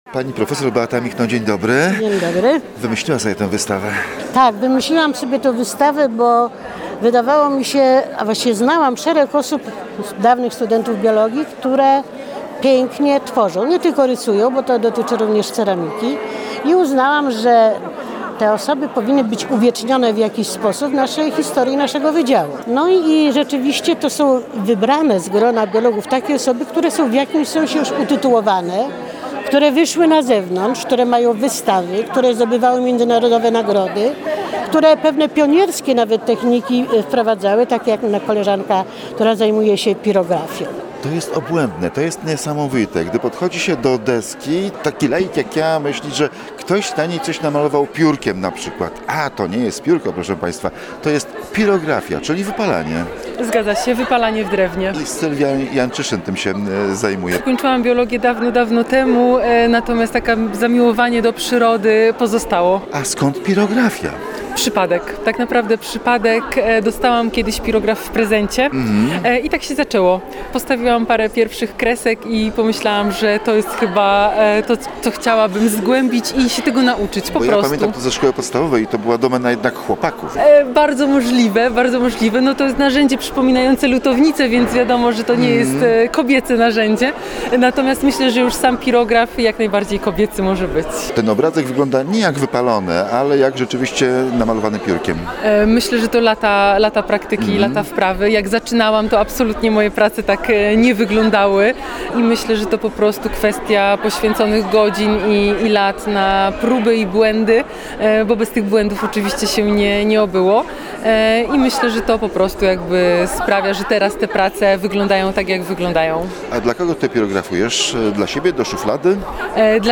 Posłuchaj rozmów zarejestrowanych podczas wernisażu